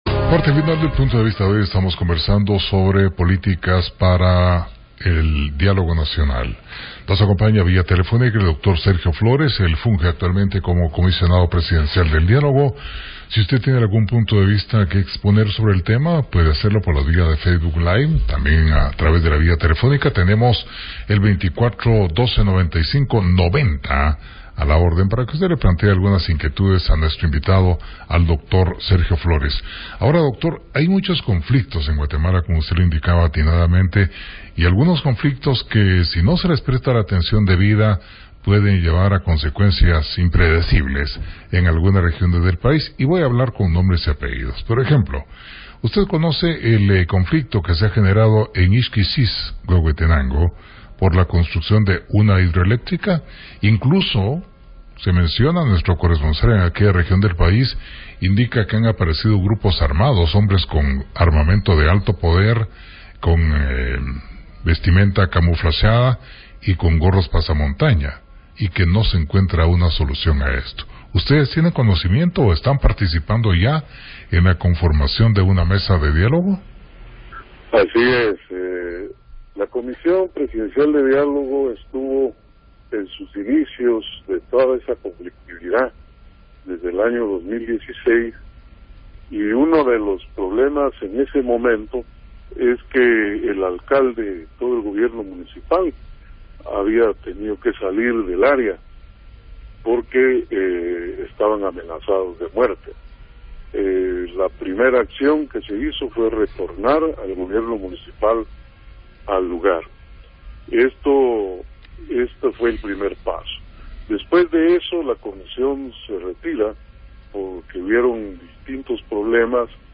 PUNTO DE VISTA / RADIO PUNTO: Entrevista